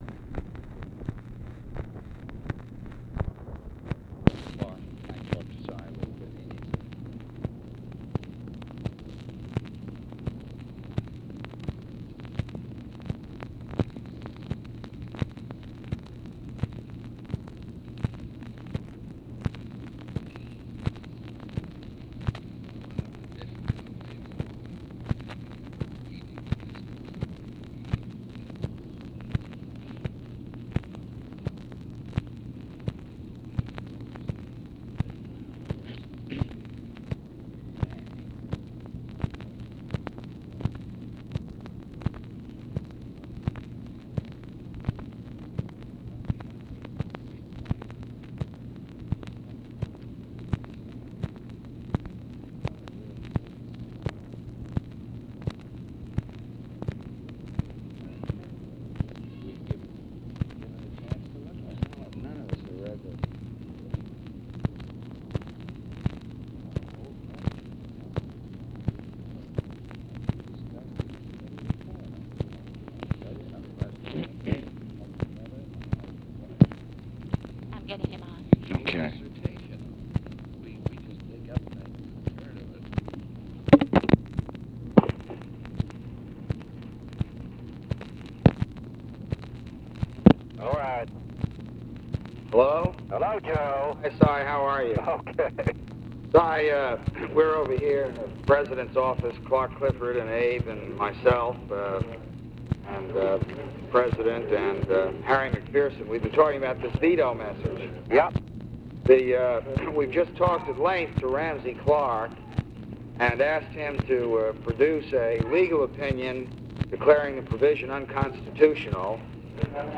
Conversation with CYRUS VANCE, JOSEPH CALIFANO, CLARK CLIFFORD, ABE FORTAS and OFFICE CONVERSATION, August 21, 1965
Secret White House Tapes